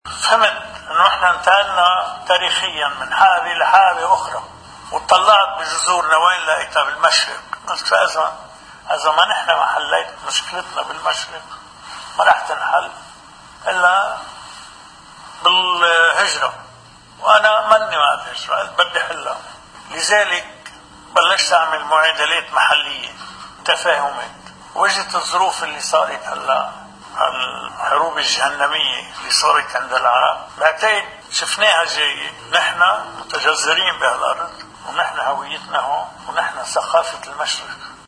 مقتطف من حديث الرئيس عون أمام لقاء مسيحيي المشرق: